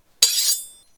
Other Sound Effects
sword.8.ogg